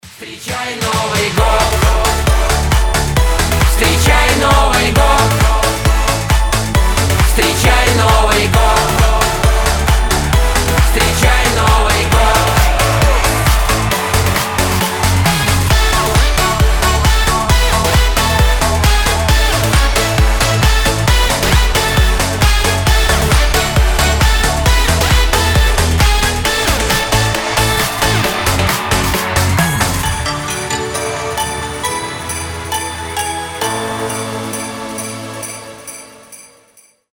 • Качество: 256, Stereo
веселые
праздничные